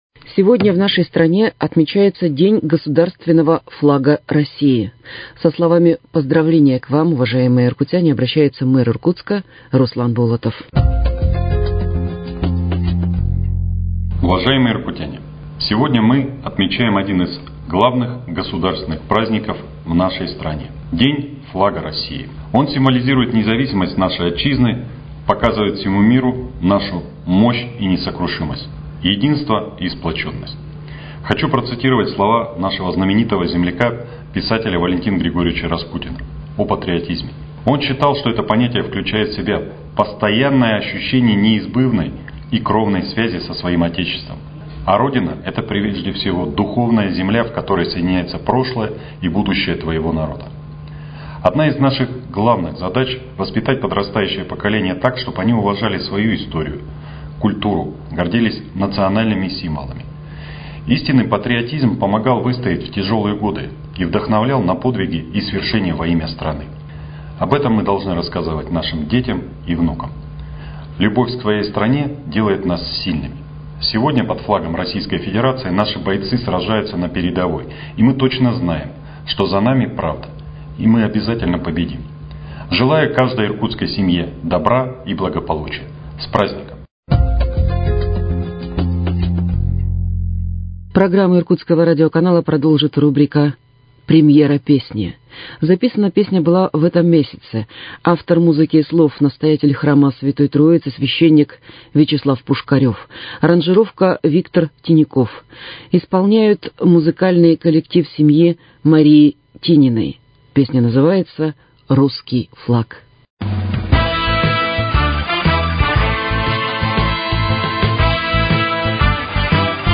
Поздравление с Днем государственного флага России от мэра Иркутска Руслана Болотова